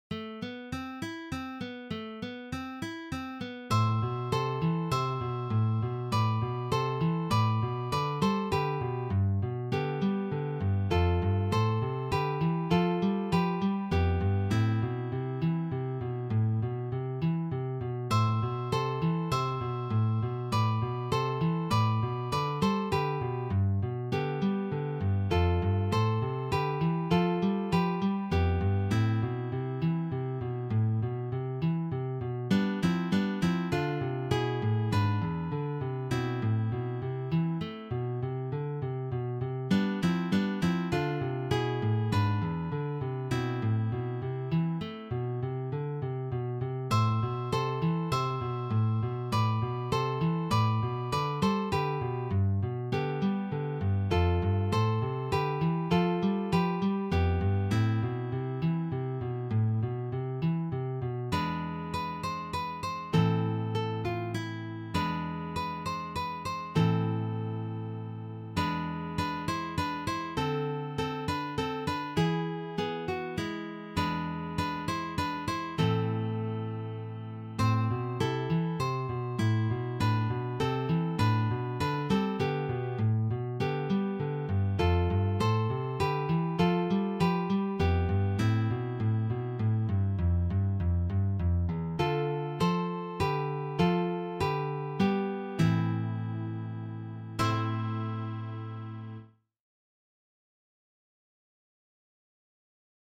arranged for three guitars
This Romantic selection